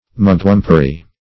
Mugwumpery \Mug"wump`er*y\, Mugwumpism \Mug"wump*ism\, n.